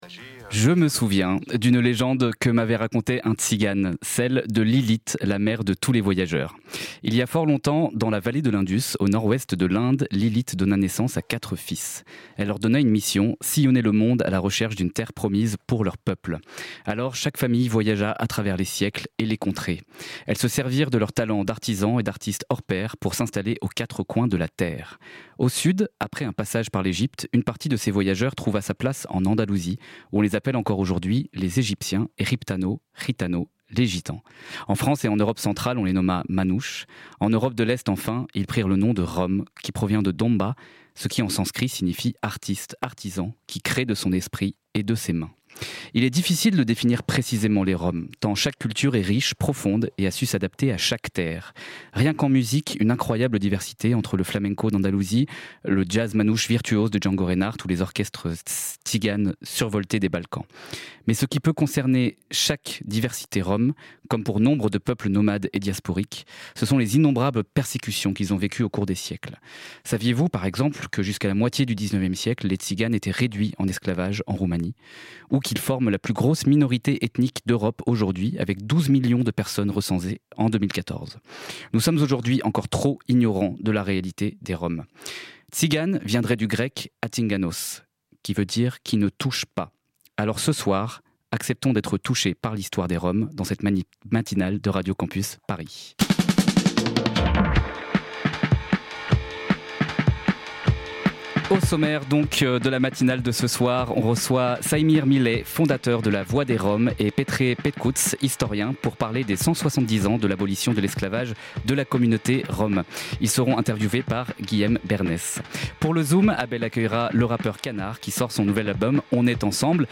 & Canard Partager Type Magazine Société Culture lundi 16 février 2026 Lire Pause Télécharger Ce soir